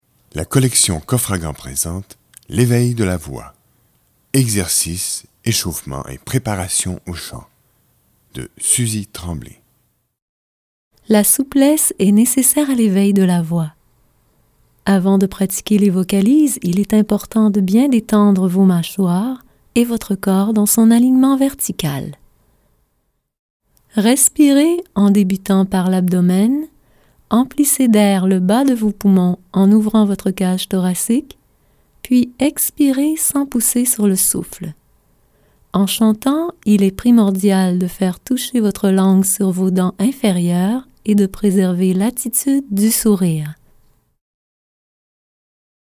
L'éveil de la voix Éxercices, Échauffements et préparation au chant
Il est proposé de chanter les vocalises avec joie pour l’éveil de la voix!